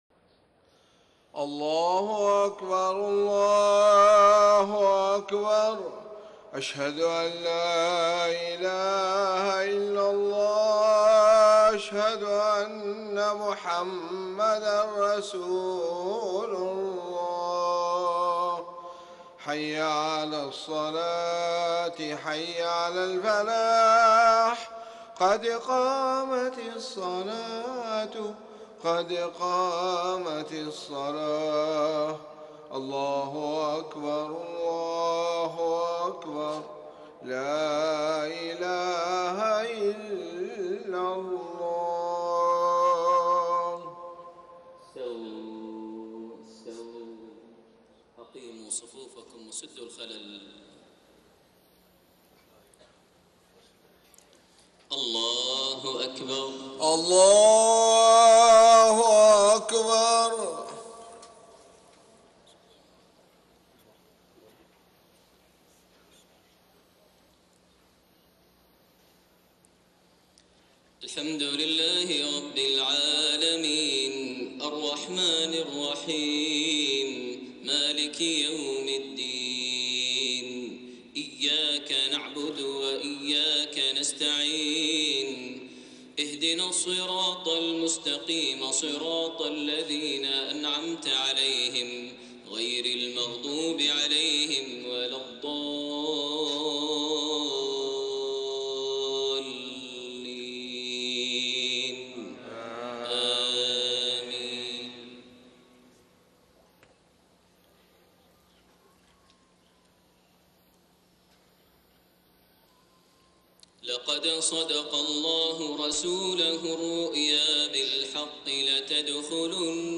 Maghrib prayer from Surah Al-Fath > 1433 H > Prayers - Maher Almuaiqly Recitations